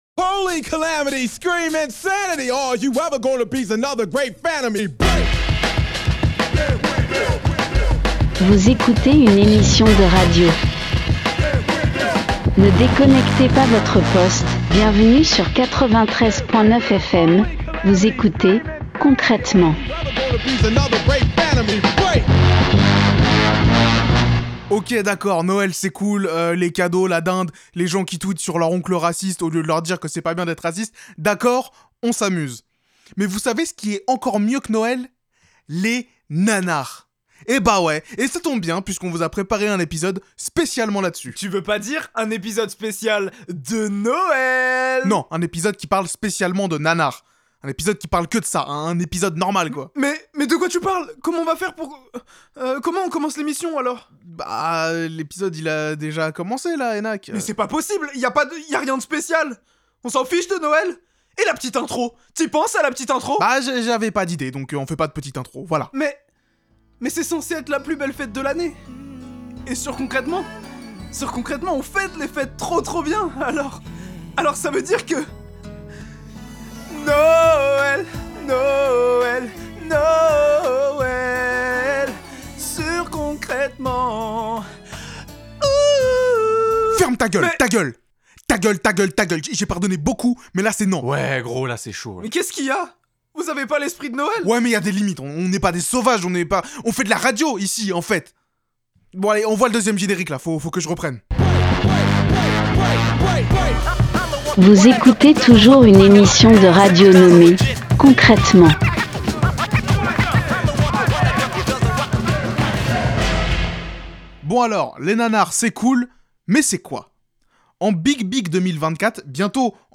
Partager Type Création sonore Société vendredi 13 décembre 2024 Lire Pause Télécharger Ok, ok.